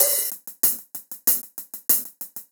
Index of /musicradar/ultimate-hihat-samples/95bpm
UHH_AcoustiHatA_95-03.wav